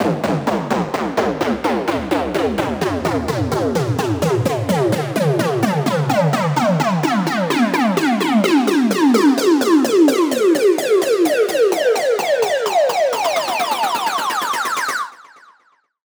EDM Rise.wav